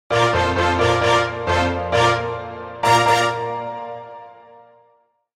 Mission Completed Sound Effect
Winning-game-sound-effect.mp3